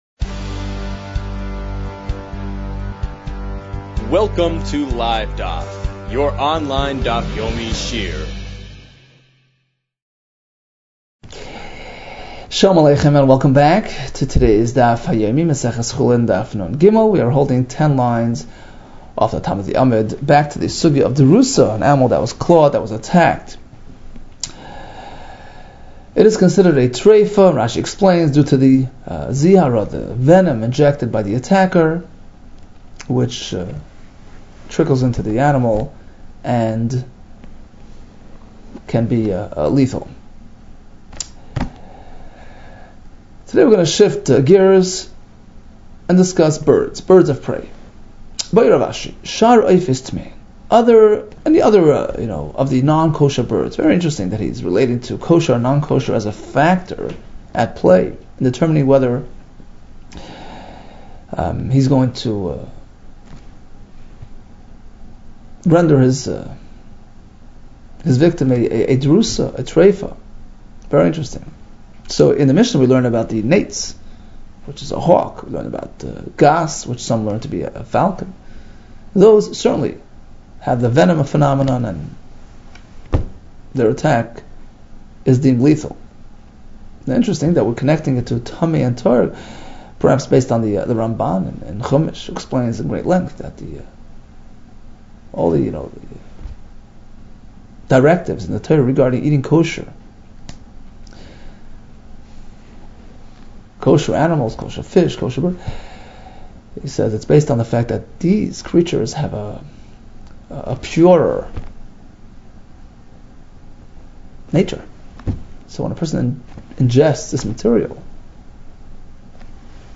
Chulin 53 - חולין נג | Daf Yomi Online Shiur | Livedaf